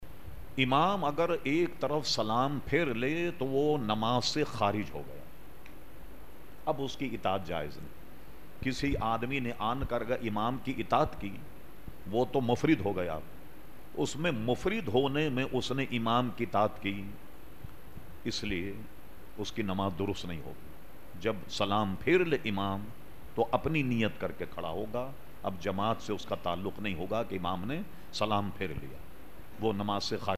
Q/A Program held on Sunday 15 August 2010 at Masjid Habib Karachi.